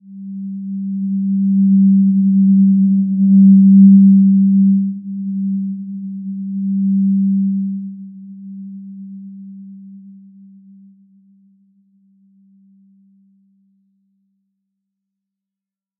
Simple-Glow-G3-mf.wav